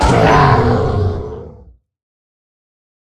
Minecraft Version Minecraft Version 25w18a Latest Release | Latest Snapshot 25w18a / assets / minecraft / sounds / mob / ravager / death1.ogg Compare With Compare With Latest Release | Latest Snapshot